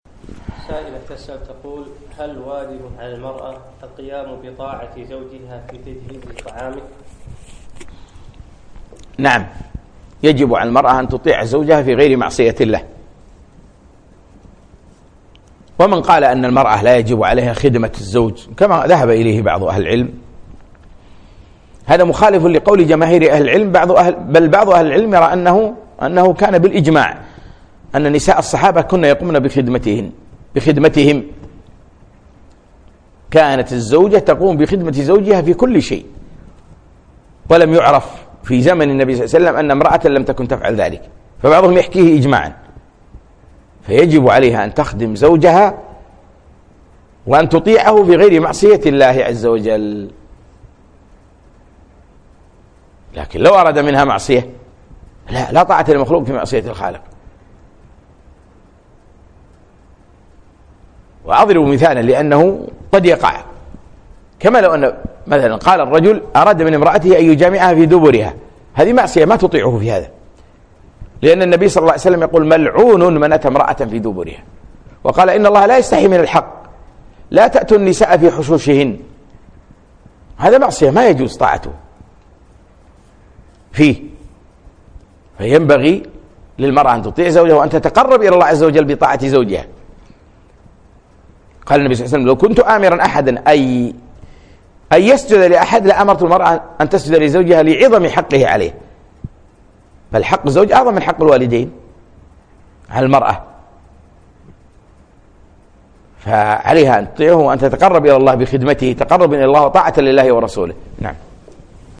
من محاضرة وقفات من سورة النور أقيمت في مركز نعيمة الدبوس صباحي الاثنين 3 4 2017